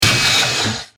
大型ロボットの駆動音4.mp3